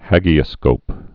(hăgē-ə-skōp, hājē-)